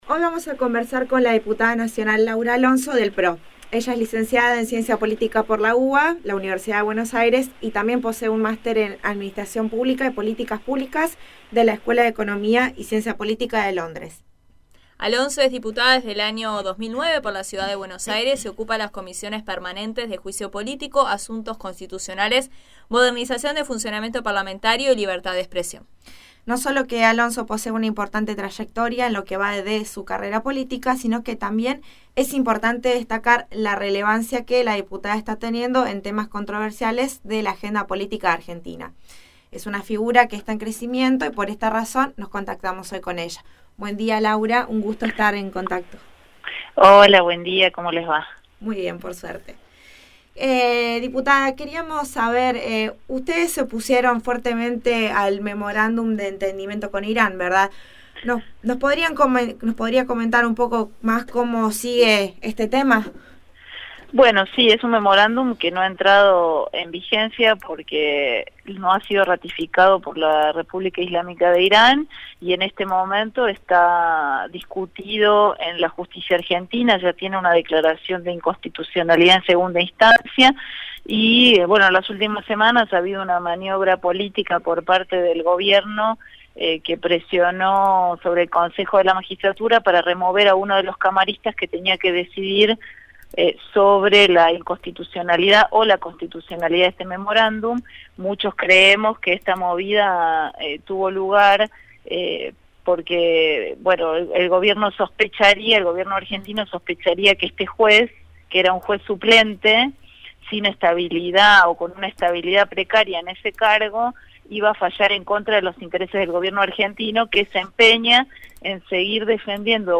Audio: Dialogo en profundidad con Diputada Nacional Laura Alonso del PRO, Argentina
El pasado viernes, conversamos con la Diputada Nacional por del PRO, de la República Argentina, Laura Alonso .